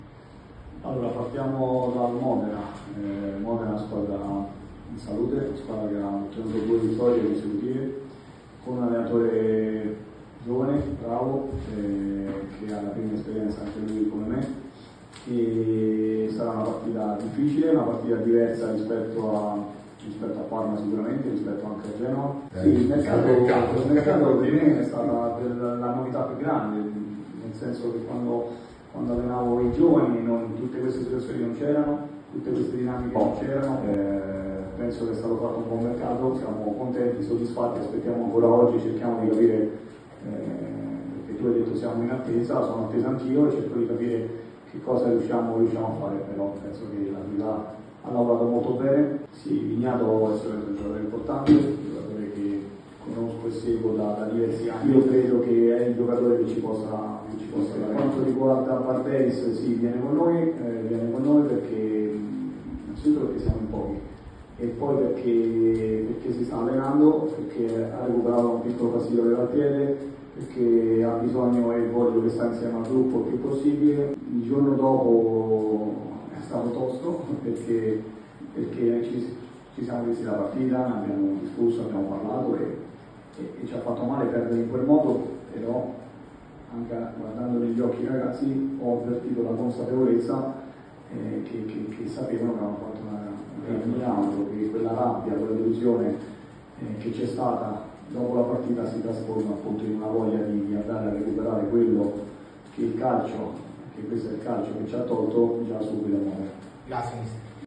Un estratto della conferenza stampa di vigilia di mister Aquilani.